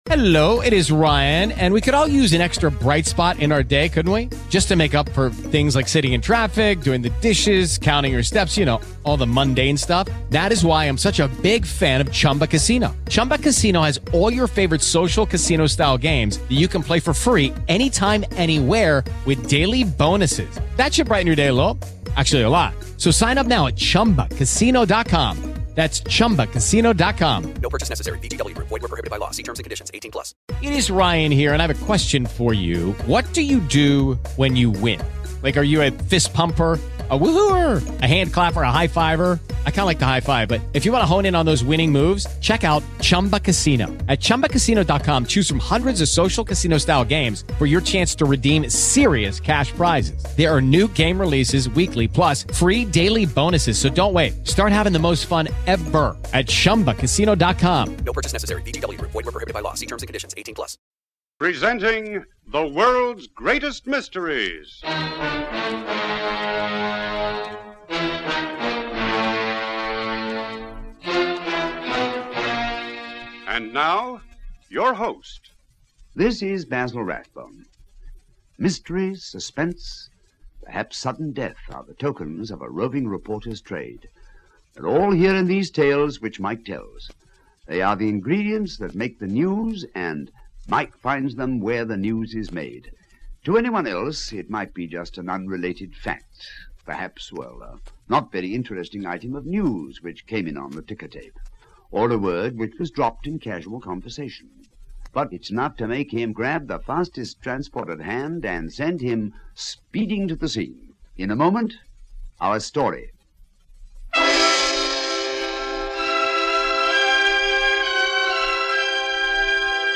Europe Confidential, a classic from the golden age of radio!